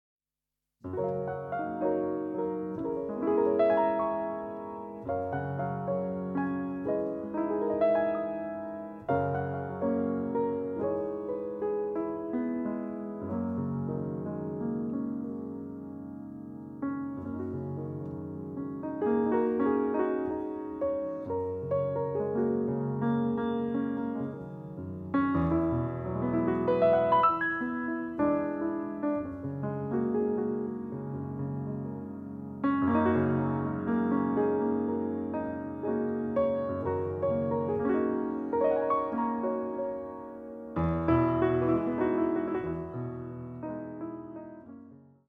いわゆる「イージーリスニング」と呼ばれる、1920〜50年代にヒットした曲を中心とした選曲。
ハワイに行ったことがある人もない人も、優しい海風のようなこのピアノに、ただ身を任せてください。